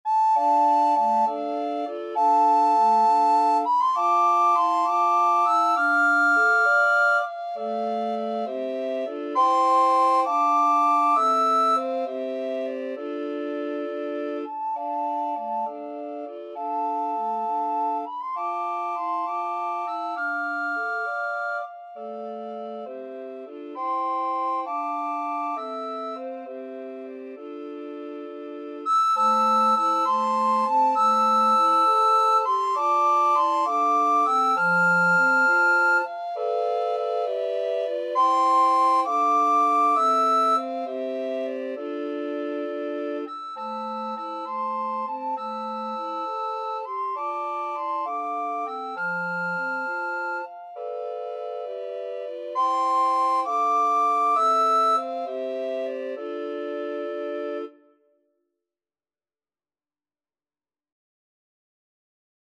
Free Sheet music for Recorder Quartet
Soprano RecorderAlto RecorderTenor RecorderBass Recorder
Traditional Music of unknown author.
D minor (Sounding Pitch) (View more D minor Music for Recorder Quartet )
Gracefully
6/8 (View more 6/8 Music)
Classical (View more Classical Recorder Quartet Music)